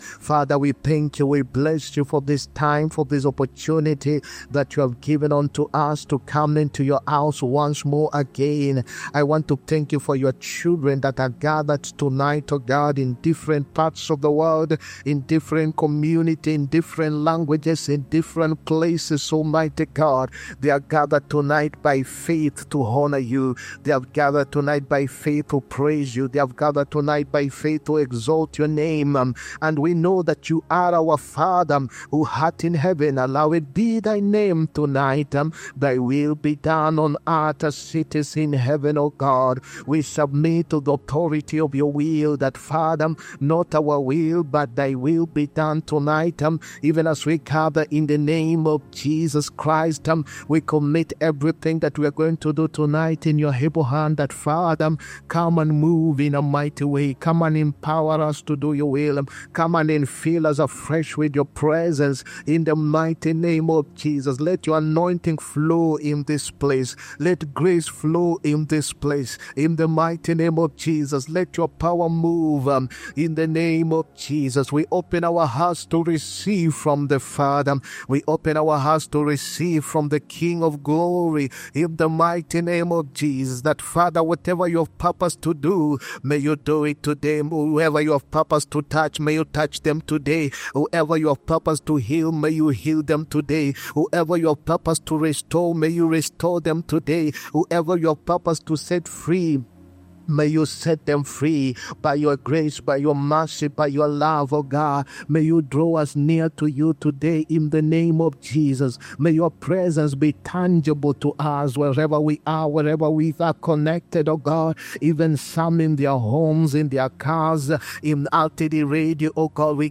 HEALING-PROPHETIC-AND-DELIVERANCE-SERVICE